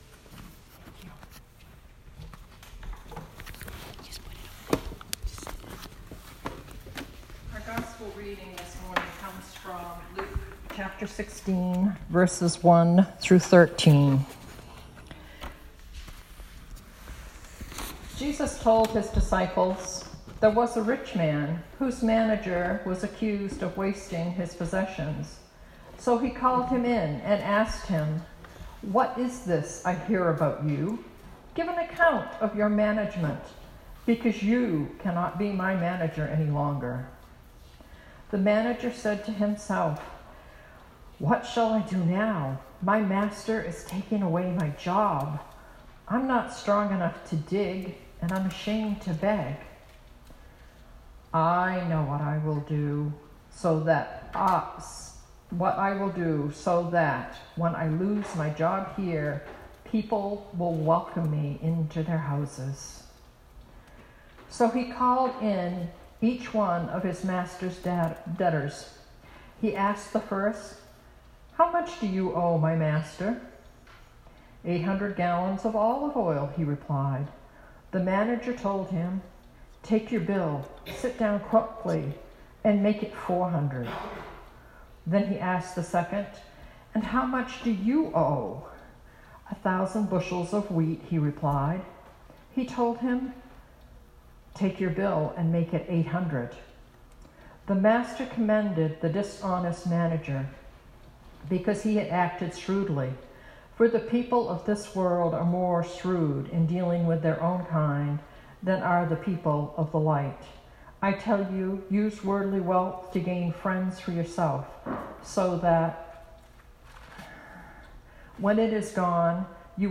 Sermon 2019-09-22